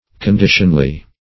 Meaning of conditionly. conditionly synonyms, pronunciation, spelling and more from Free Dictionary.
conditionly - definition of conditionly - synonyms, pronunciation, spelling from Free Dictionary Search Result for " conditionly" : The Collaborative International Dictionary of English v.0.48: Conditionly \Con*di"tion*ly\, adv.